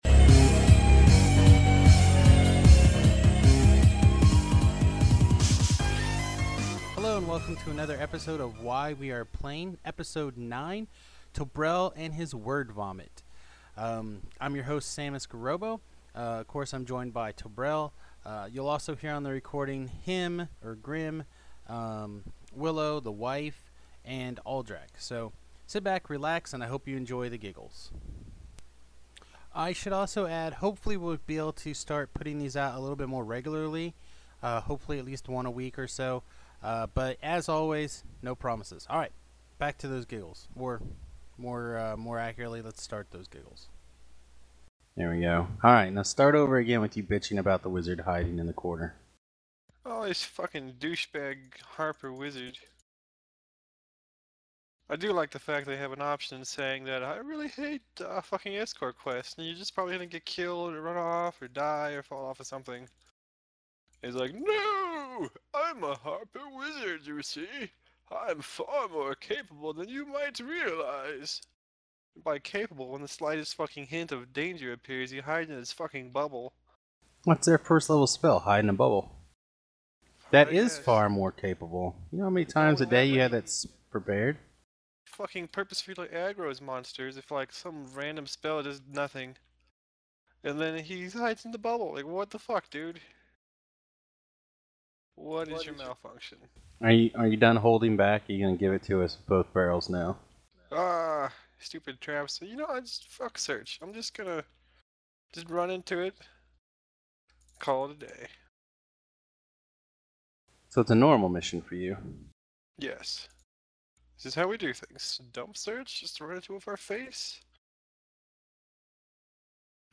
I know there is some audio issues but nothing i thought that would make it unplayable.
Might need to find yet another headset. Or better yet a mixing board. That could clean up a lot of these issues.